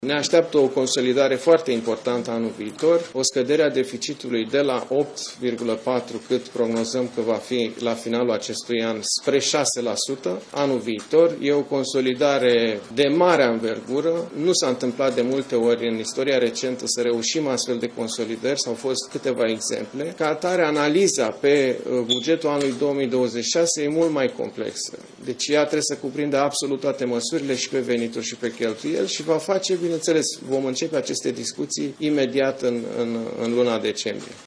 Ministrul Finanțelor, Alexandru Nazare: „Analiza pe bugetul anului 2026 e mult mai complexă”